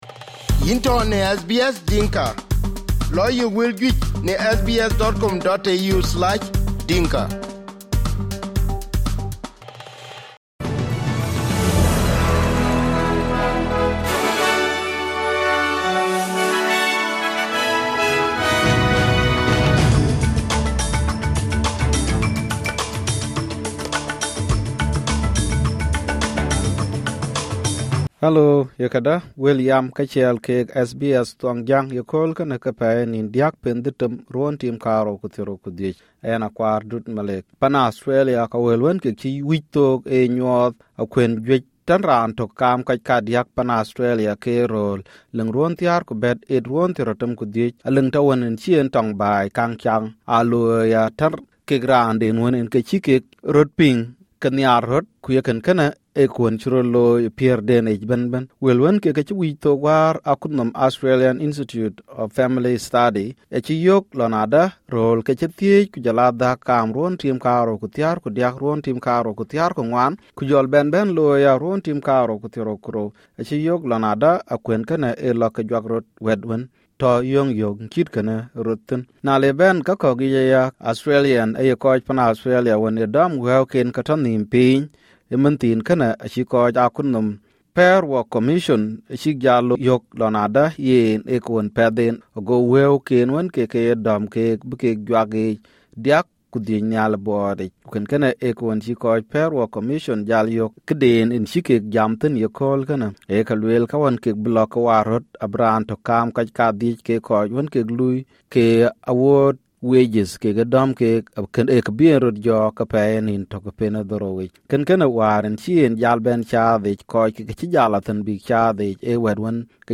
Wel SBS Dinka Newsflash ee Tuesday akol niin 3 pen Detem ruön 2025